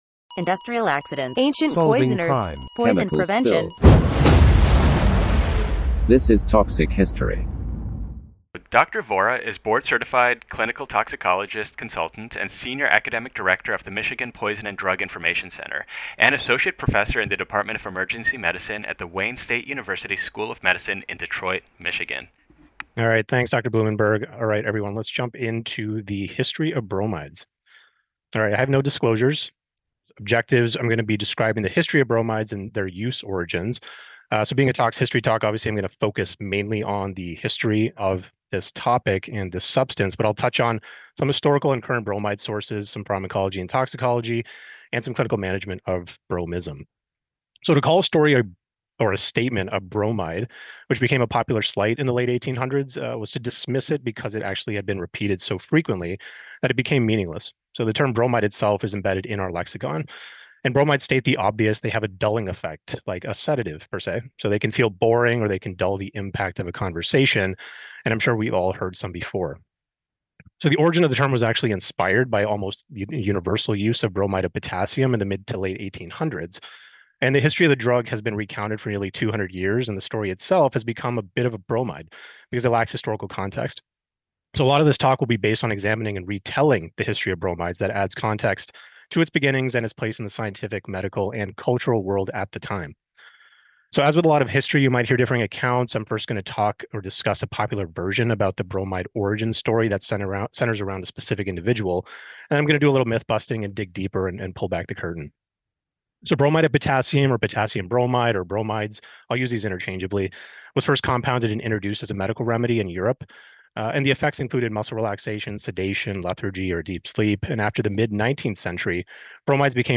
Toxic History! is a narrative medicine lecture series where medical experts tell you stories from the history of poison.